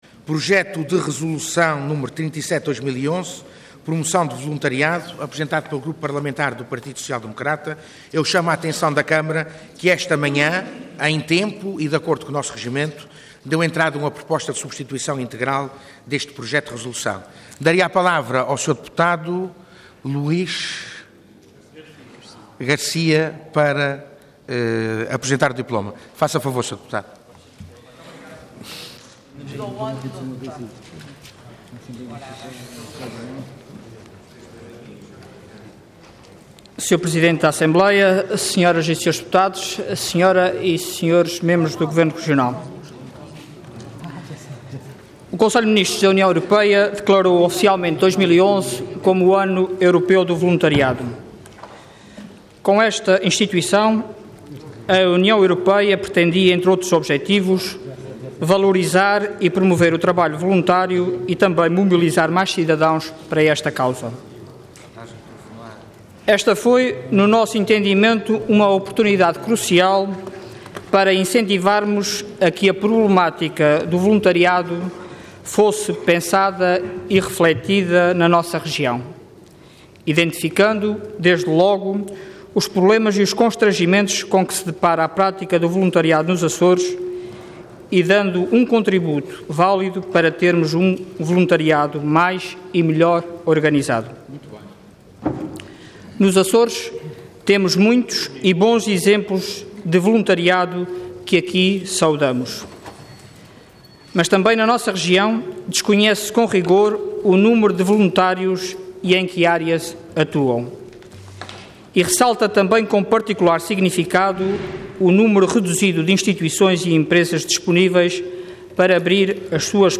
Intervenção Projeto de Resolução Orador Luís Garcia Cargo Deputado Entidade PSD